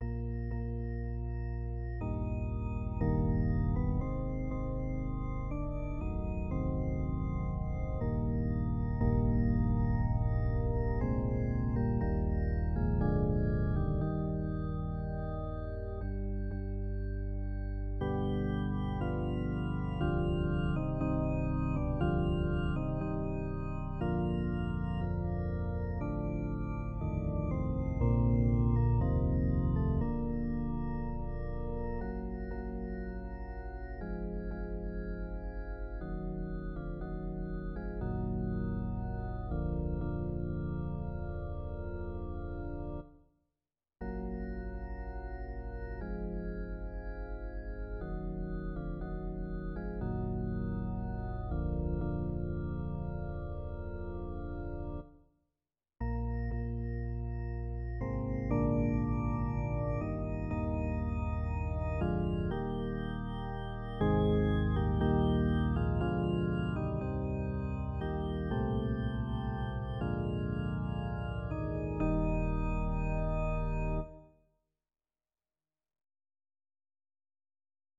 Vegyes karra